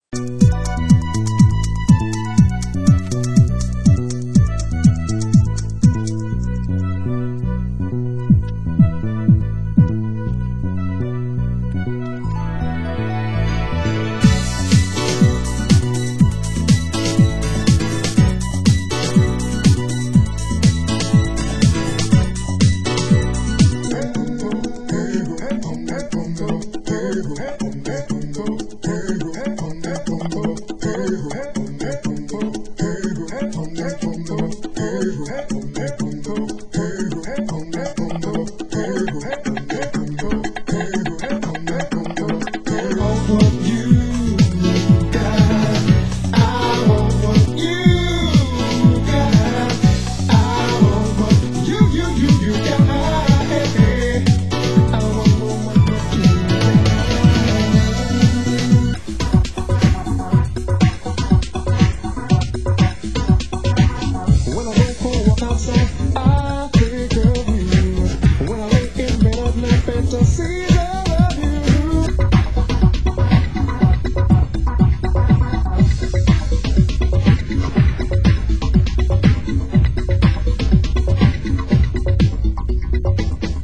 Genre: US House
Extended RAdio, Chantapella, Radio, Club, Dub